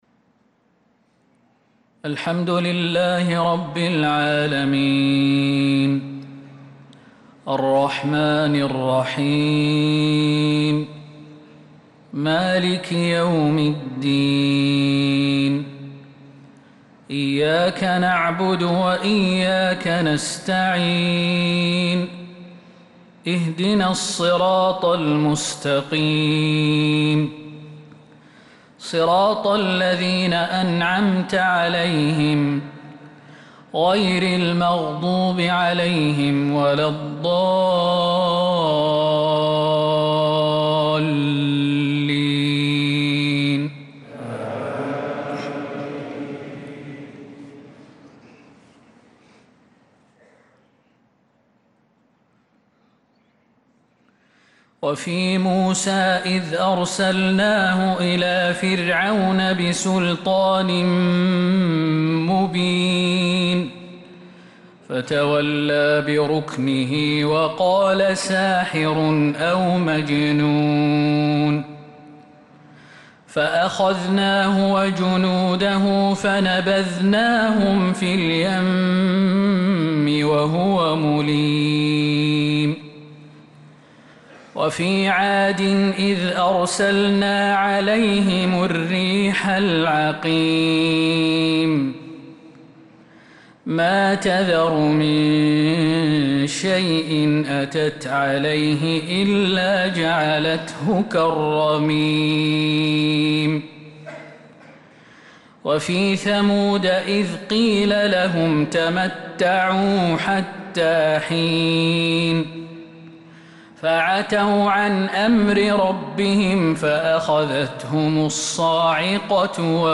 صلاة العشاء للقارئ خالد المهنا 16 ربيع الآخر 1446 هـ